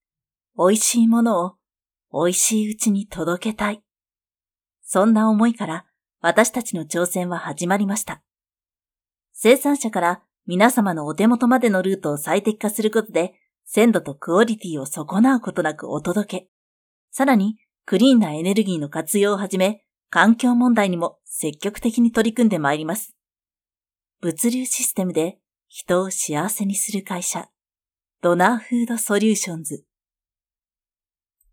V O I C E
30秒CM風